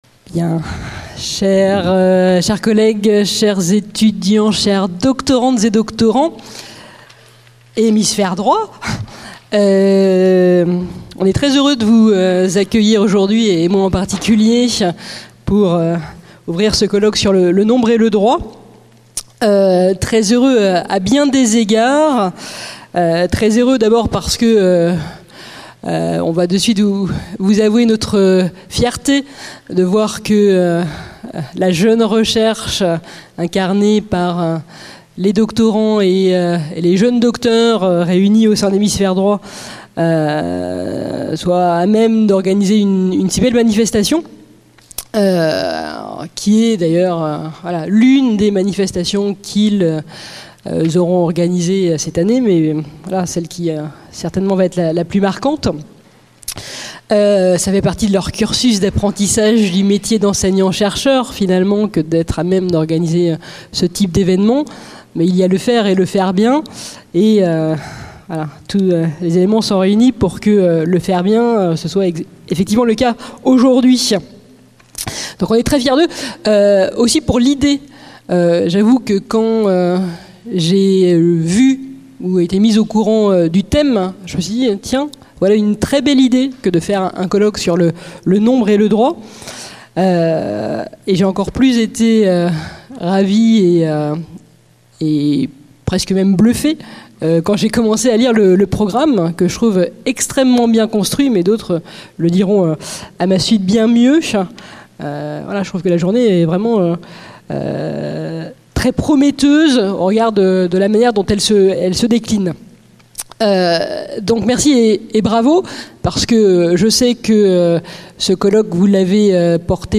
Colloque organisé par l'association Hémisphère Droit